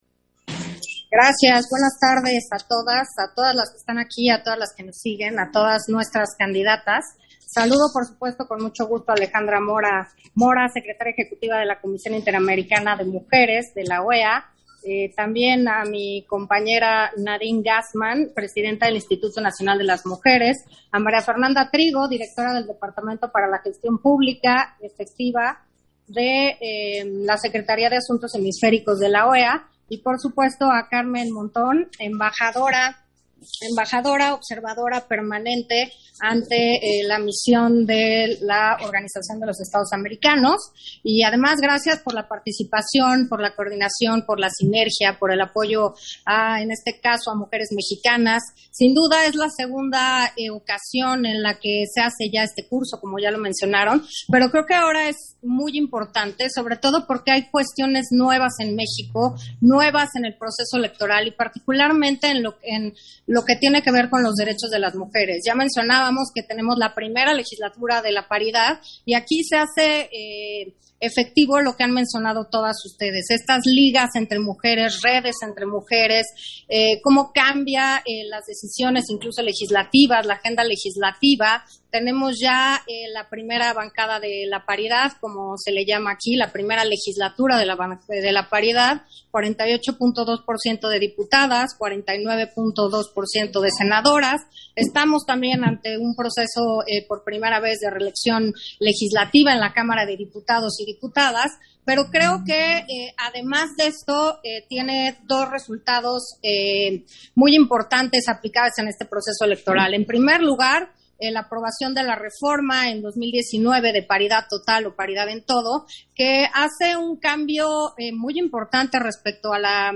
Intervención de Carla Humphrey, durante la inauguración del curso para Cadidatas Electorales, Griselda Álvarez Ponce de León